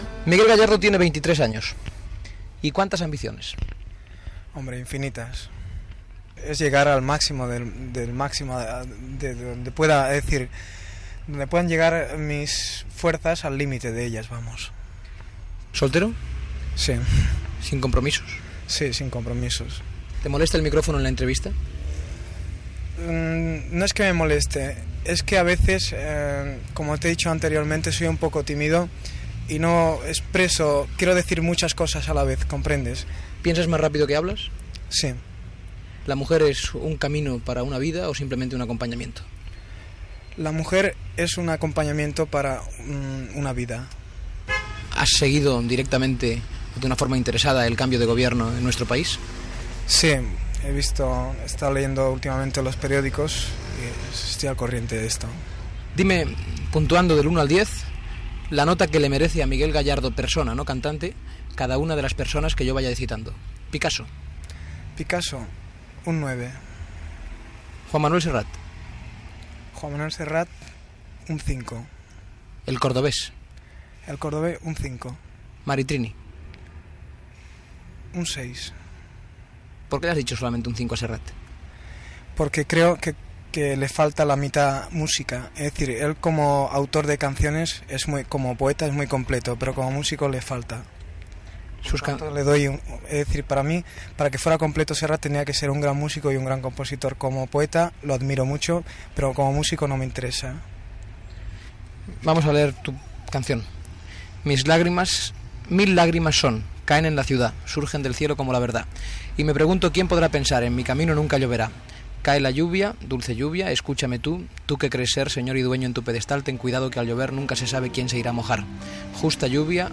Entrevista al cantant Miguel Gallardo
Entreteniment